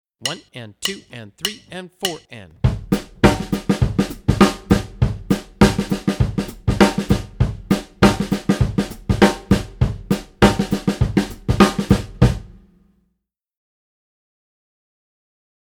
NEW ORLEANS R&B
bpm 102